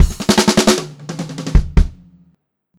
152SPFILL2-R.wav